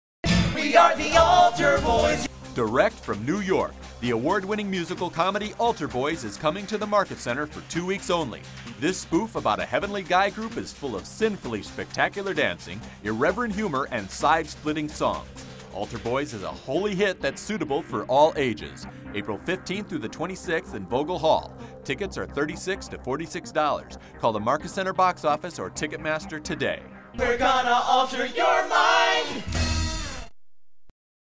AlterBoyz Radio Commercial